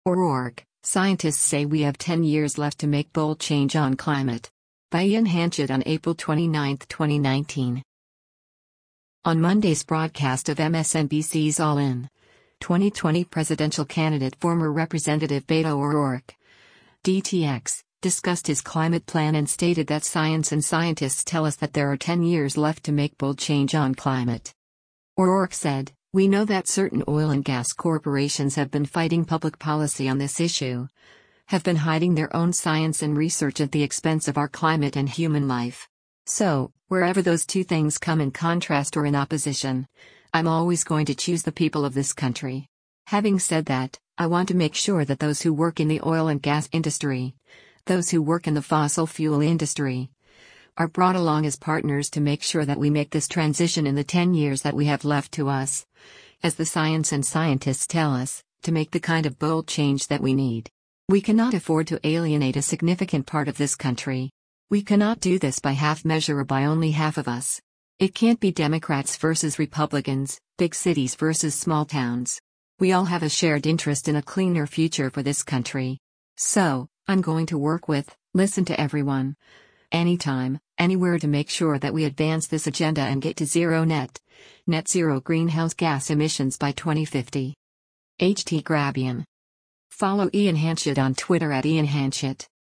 On Monday’s broadcast of MSNBC’s “All In,” 2020 presidential candidate former Representative Beto O’Rourke (D-TX) discussed his climate plan and stated that “science and scientists tell us” that there are ten years left to make “bold change” on climate.